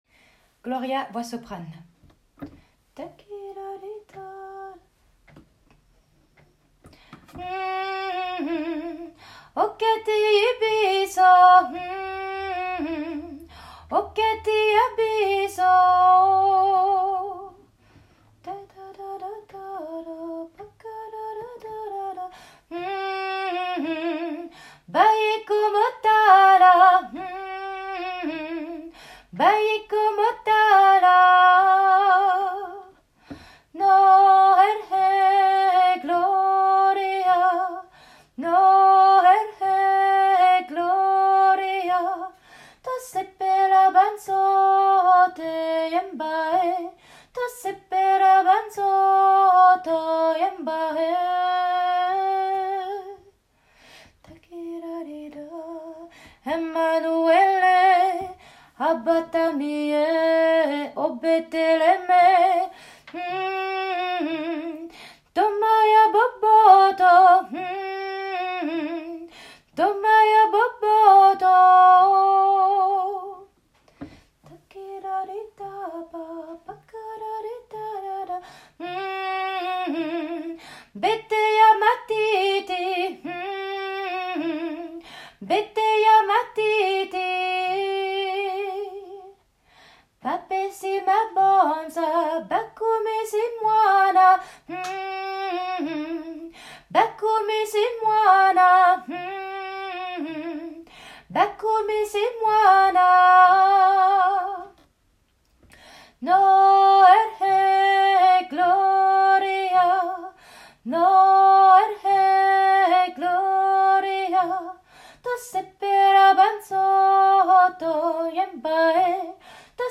Soprane
No--l-Gloria---soprane.m4a